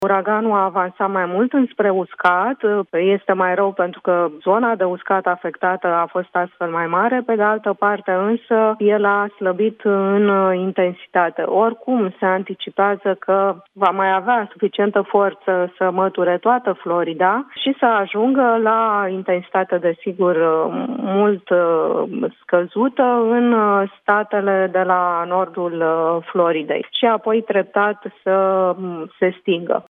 Directorul Administrației Naționale de Meteorologie, Florinela Georgescu, a explicat la Europa FM decizia colegilor de dincolo de Atlantic.